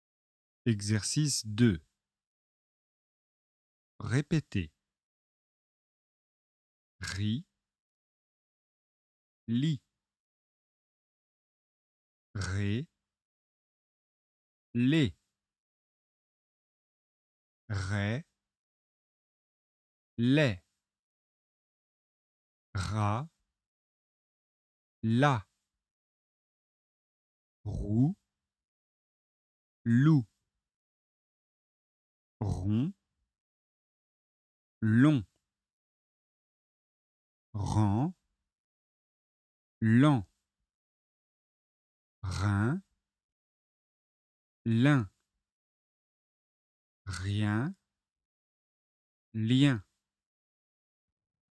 Exercice 2 : [r]  [l] placés en début de mot.
Répétez.